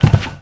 FOOTSTEP
1 channel